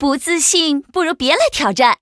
文件 文件历史 文件用途 全域文件用途 Choboong_tk_03.ogg （Ogg Vorbis声音文件，长度0.0秒，0 bps，文件大小：27 KB） 源地址:游戏语音 文件历史 点击某个日期/时间查看对应时刻的文件。